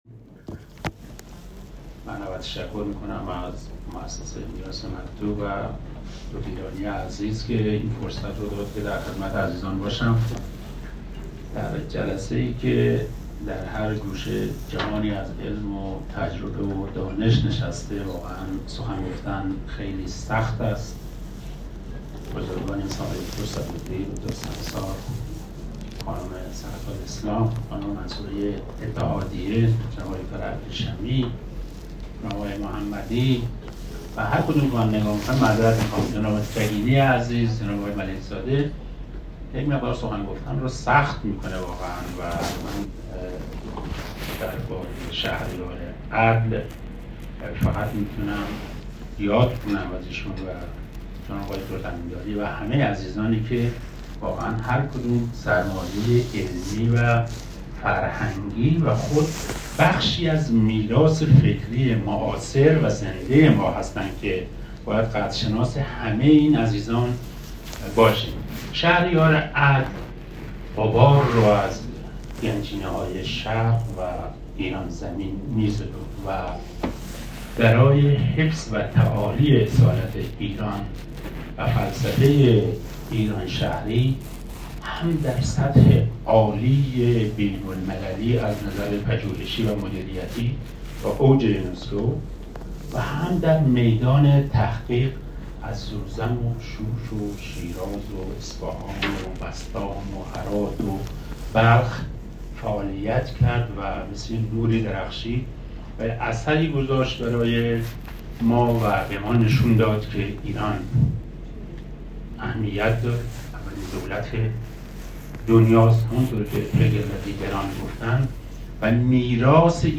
سخنرانی
در خصوص شهریارعدل و میراث فرهنگی ایران زمین در موسسه پژوهشی میراث مکتوب, شوق دیدار با حضور استادان وپیشکسوتان فرهنگ‌ ایران